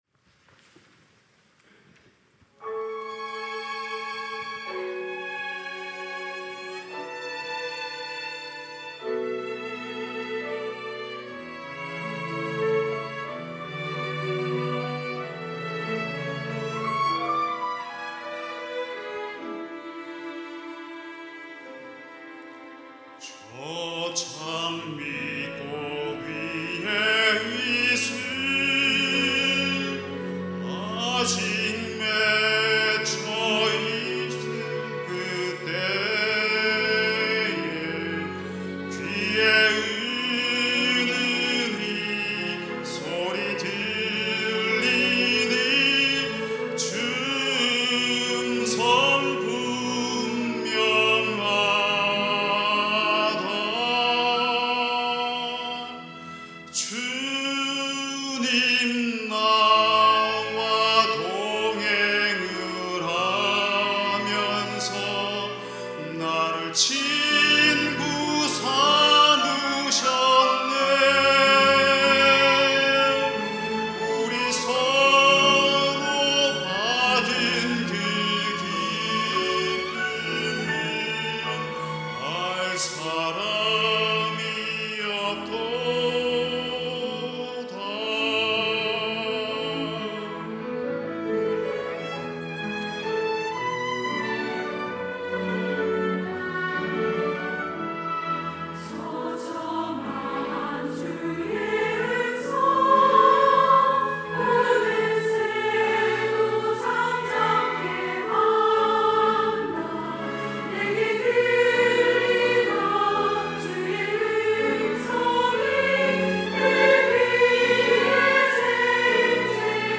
호산나(주일3부) - 저 장미꽃 위에 이슬
찬양대